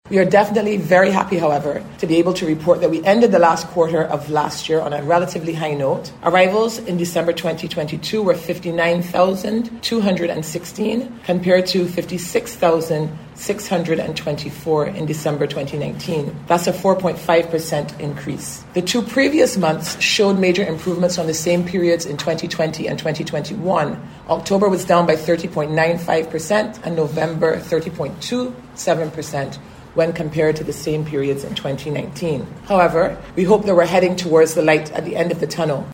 This was among the disclosures this morning during a BHTA news briefing.